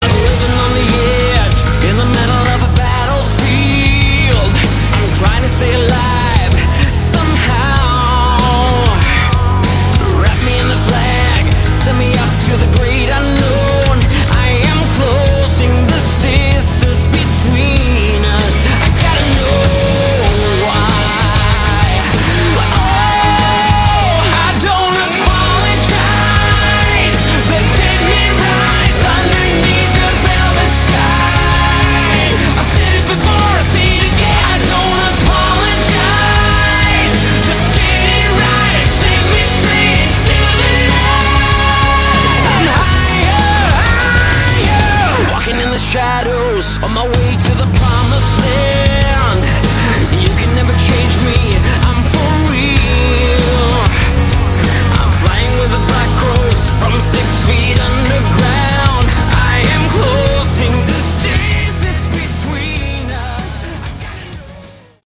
Category: Melodic Hard Rock
The voice is warm and the songs are energetic and youthful.